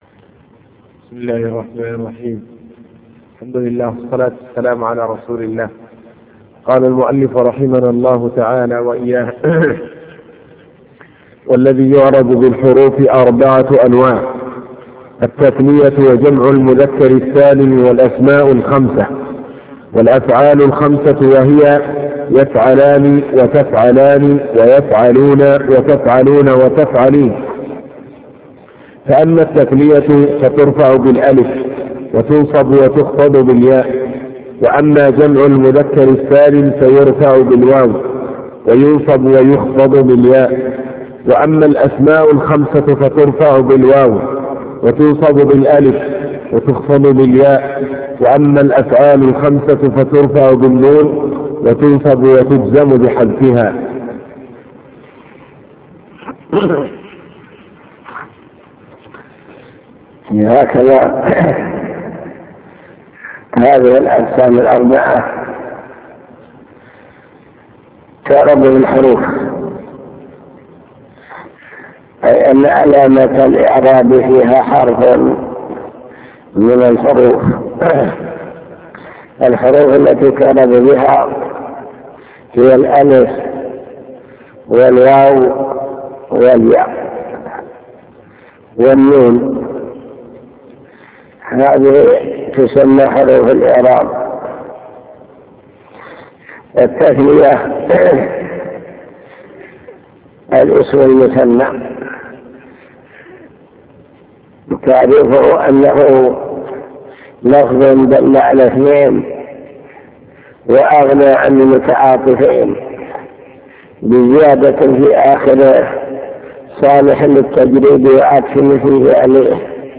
المكتبة الصوتية  تسجيلات - كتب  شرح كتاب الآجرومية باب الإعراب علامات الإعراب حروف الإعراب.. وما يعرب بها